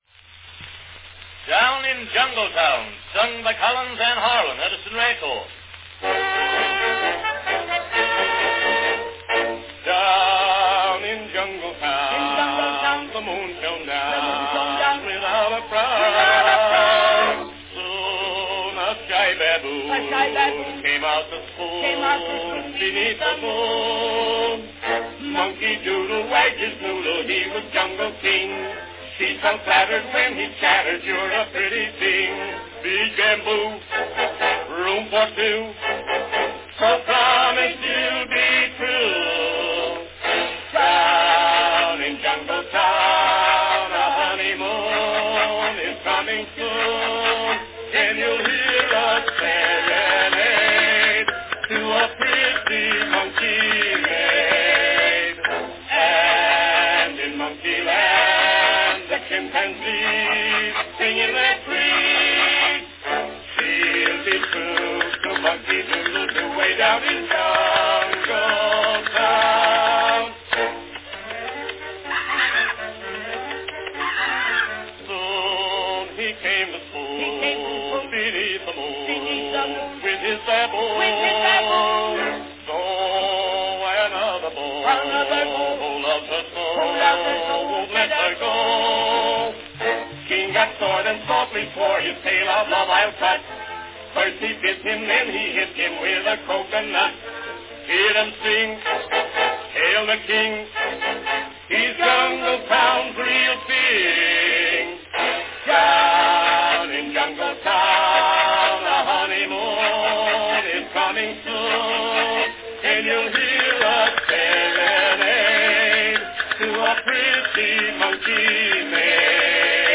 Category Duet
Performed by Arthur Collins & Byron G. Harlan
Announcement "Down in Jungle Town, sung by Collins & Harlan.  Edison record."
The great team of Arthur Collins and Byron Harlan deliver another popular recording of humorous material.
The tune is fetching and the chorus goes with a double-swing, helped considerably by a xylophone accompaniment.   Orchestra accompaniment; music, Theodore Morse; words, Edward Madden; publishers, F. B. Haviland Publishing Co., New York.